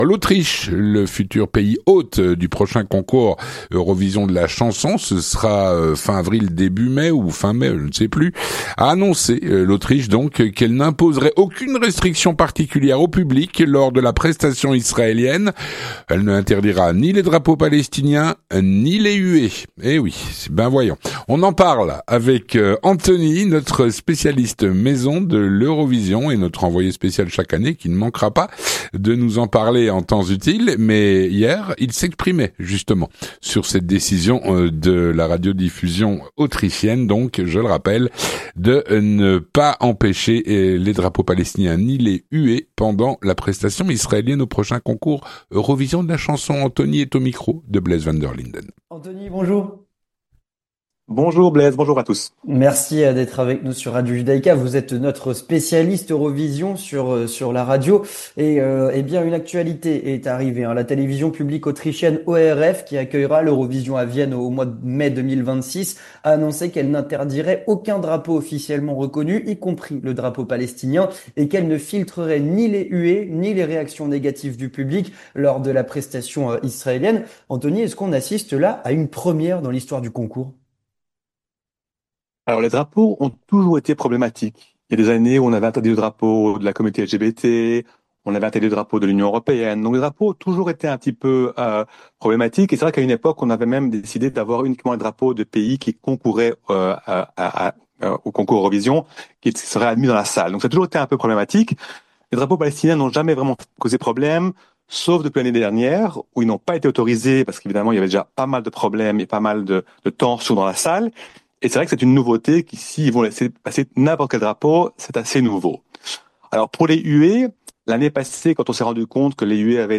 spécialiste de l’Eurovision.